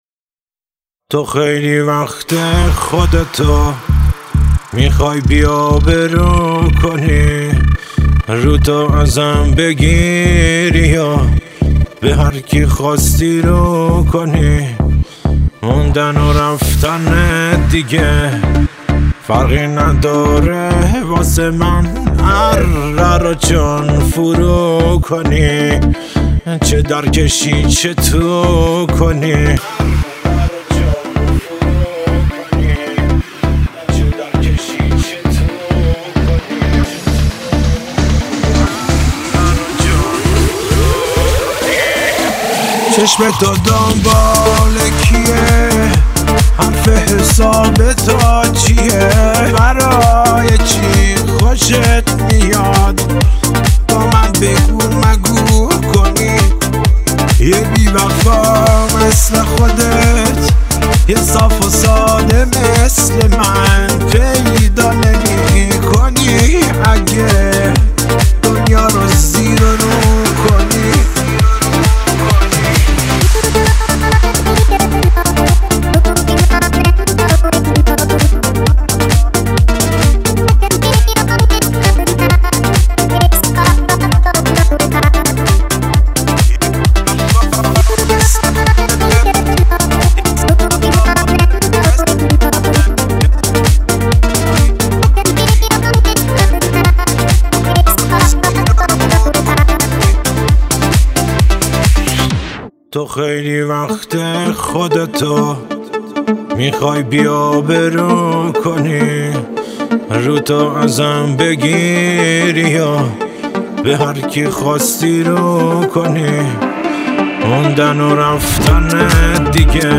گیتار الکتریک – گیتار آکوستیک
گیتار اسپانیش
گیتار باس
ساز های کوبه ای
هارمونیکا
تار